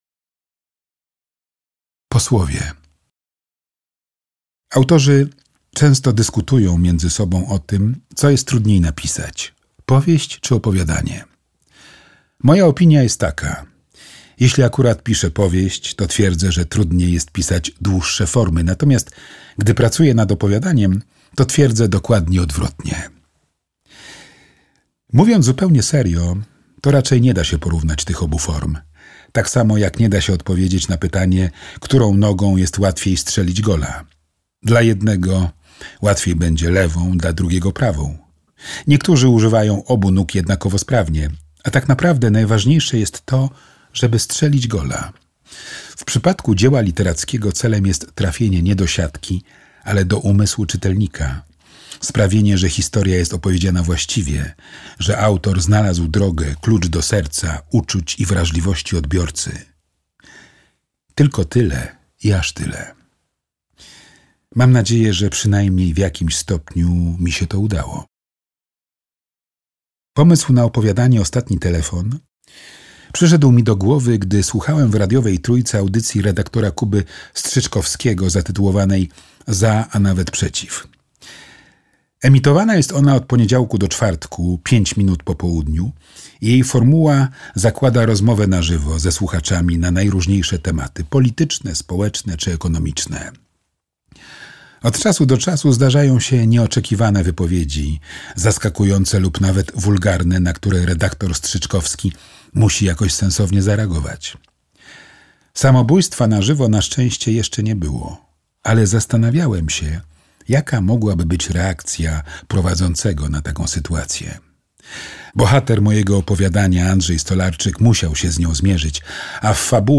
Opowiem ci mroczną historię - Stefan Darda - audiobook